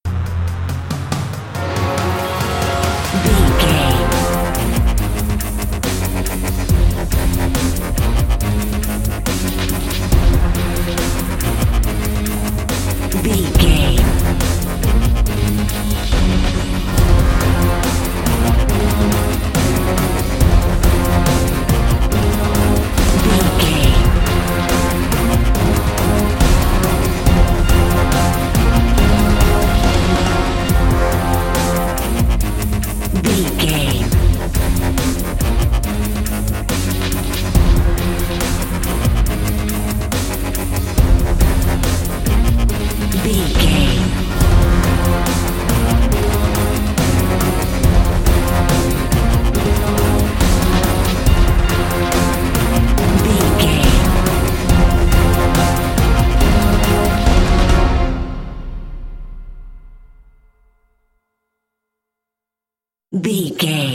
Fast paced
In-crescendo
Dorian
synthesiser
drum machine
electric guitar
orchestral hybrid
dubstep
aggressive
energetic
intense
strings
bass
synth effects
wobbles
driving drum beat